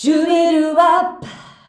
SHUBIWAP F.wav